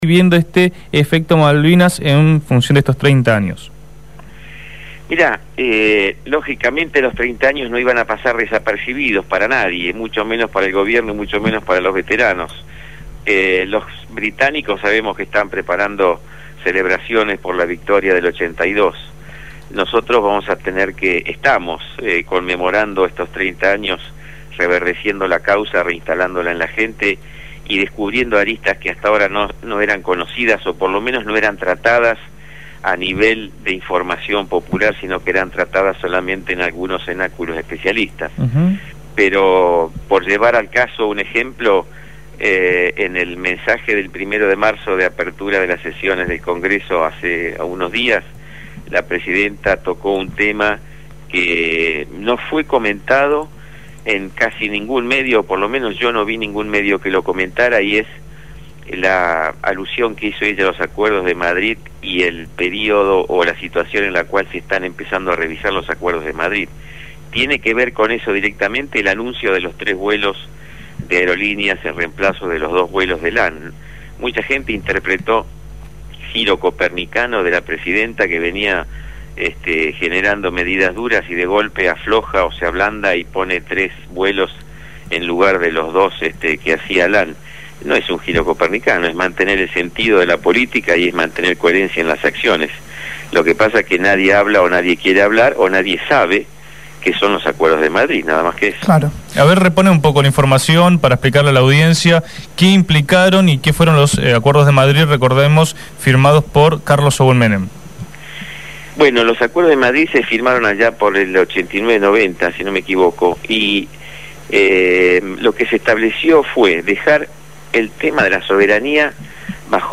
excombatiente de Malvinas